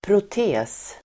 Ladda ner uttalet
Uttal: [prot'e:s]
protes.mp3